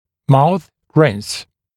[mauθ rɪns][маус ринс]ополаскиватель для полости рта